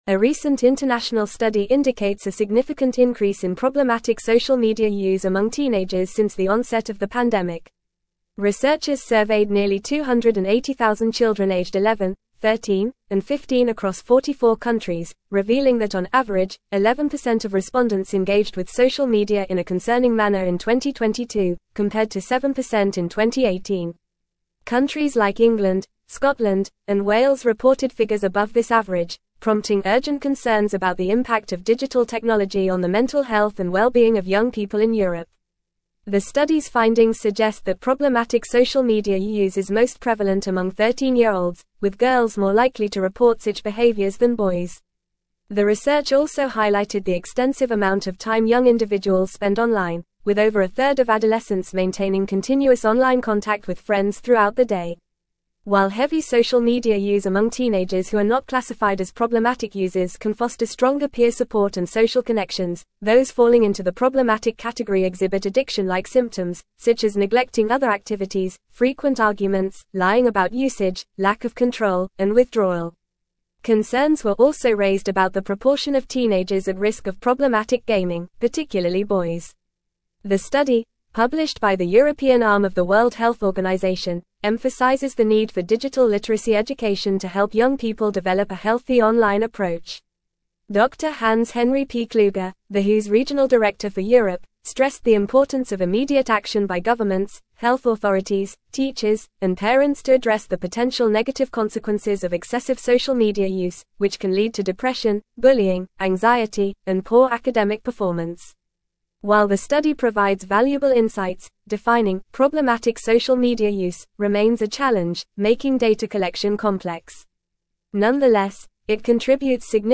Fast
English-Newsroom-Advanced-FAST-Reading-Concerning-Increase-in-Teenage-Social-Media-Use-Detected.mp3